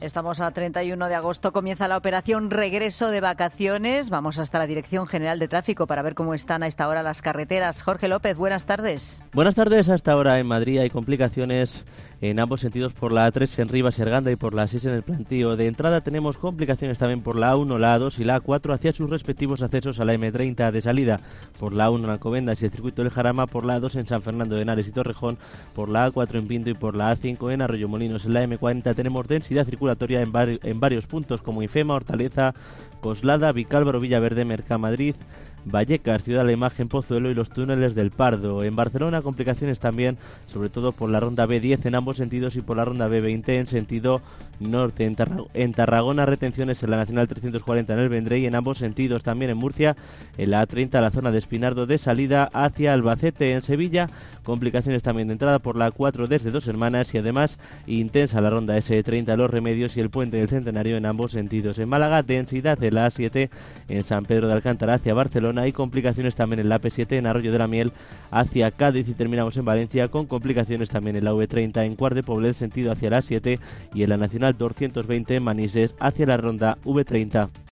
Información de la DGT en el boletín informativo de las 16.00 horas